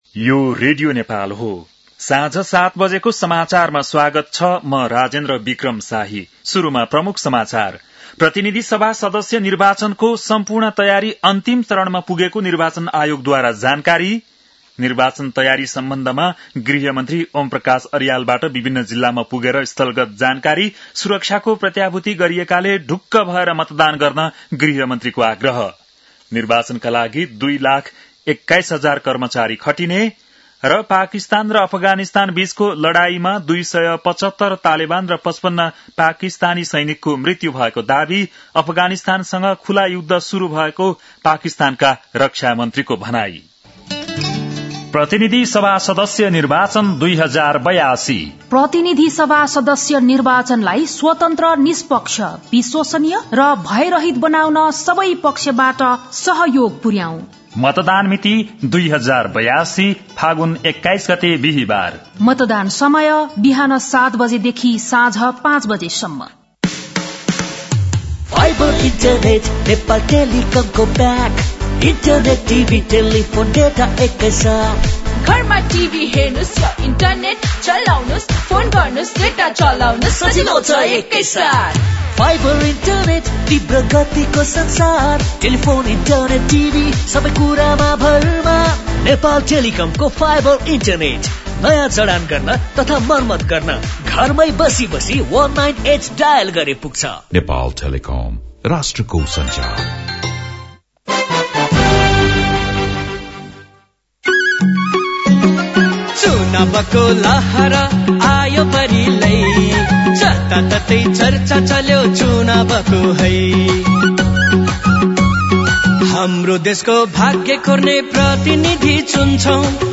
बेलुकी ७ बजेको नेपाली समाचार : १५ फागुन , २०८२